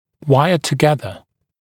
[‘waɪə tə’geðə][‘уайэ тэ’гезэ]скрепить, связать вместе проволокой